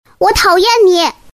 萝莉音我讨厌你音效免费音频素材下载